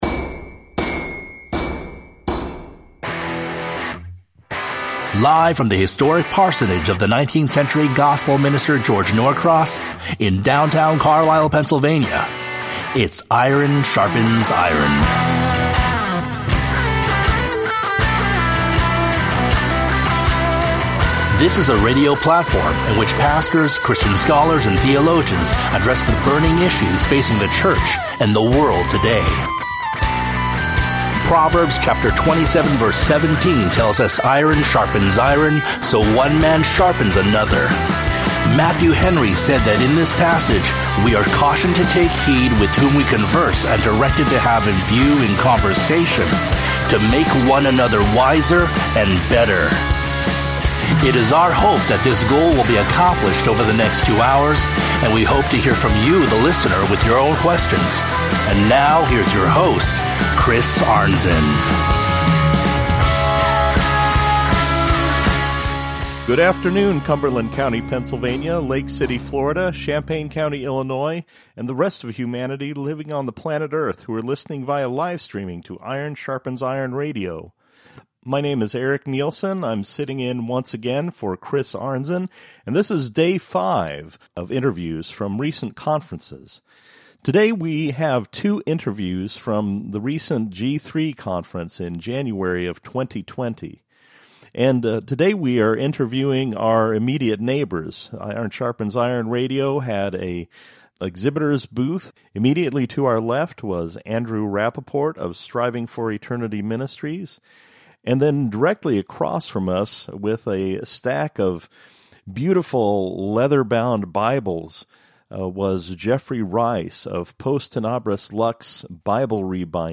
Featuring Interviews from the 2020 G3 Conference with